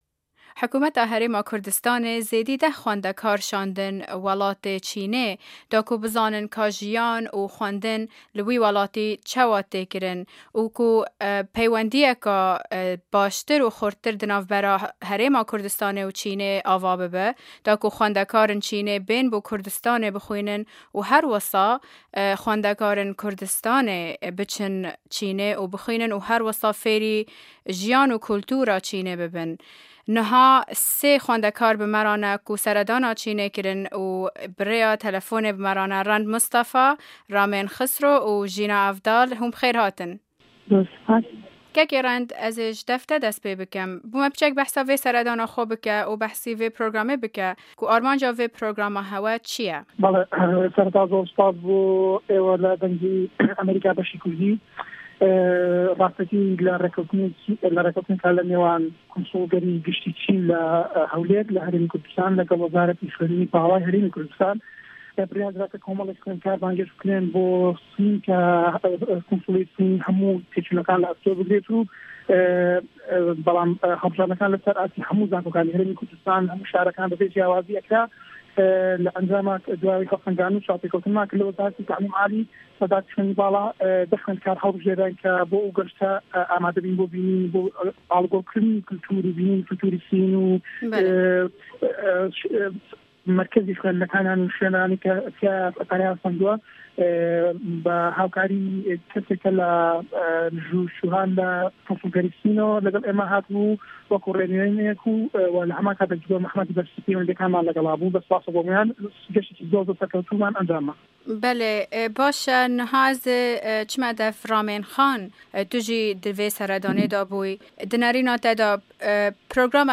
دەقی وتووێژ لەگەڵ ژمارەیەک خوێندکاری کورد